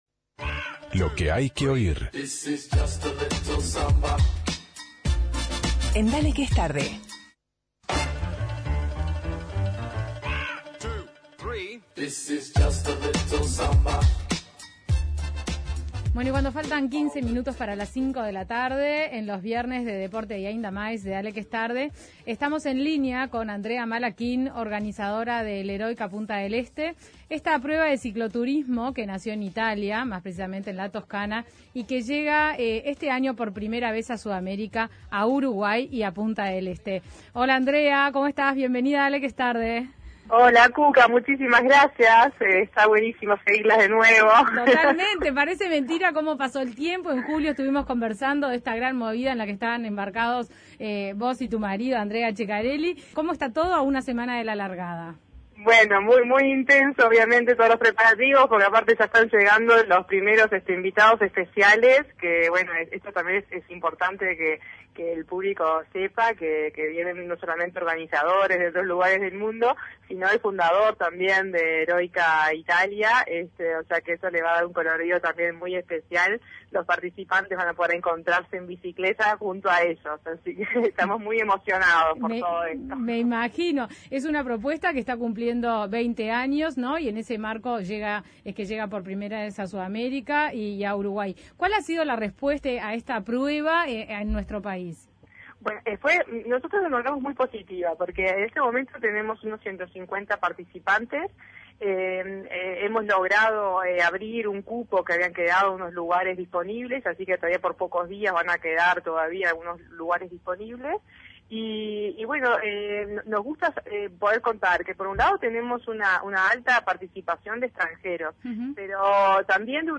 En diálogo con Dale Que Es Tarde